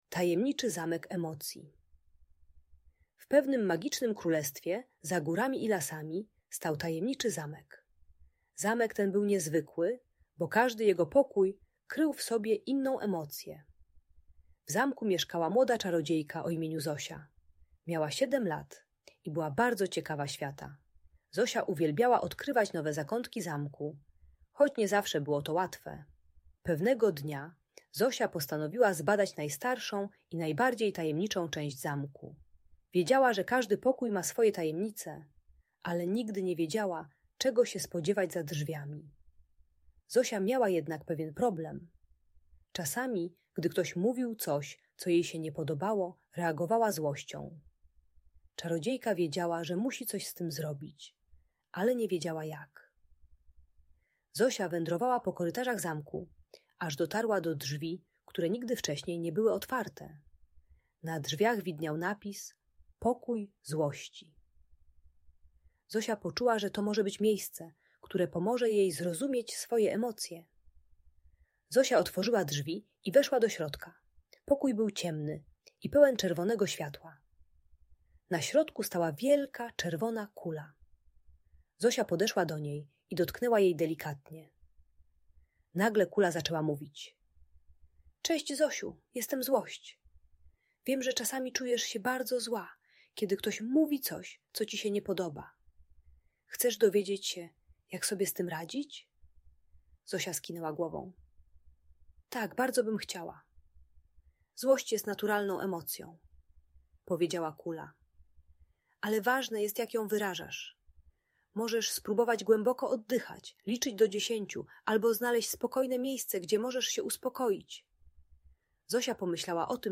Tajemniczy Zamek Emocji - Bunt i wybuchy złości | Audiobajka